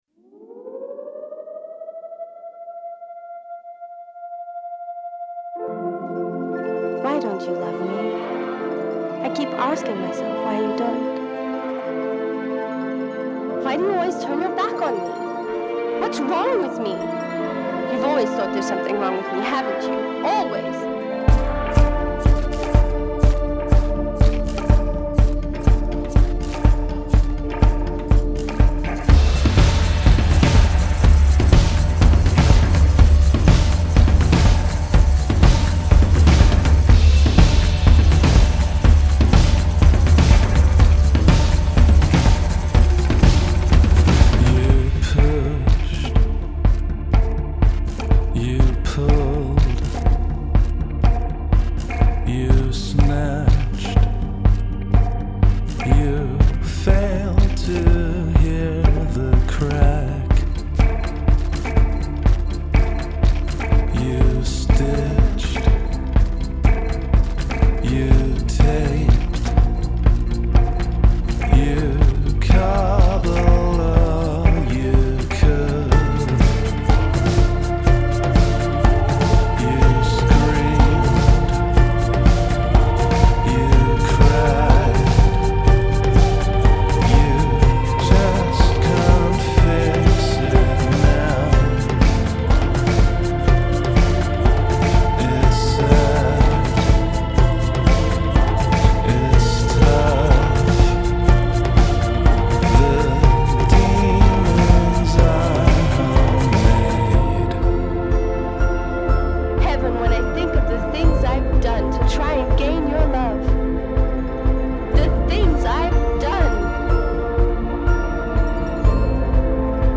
ein ausgesprochen pulsierendes stück musik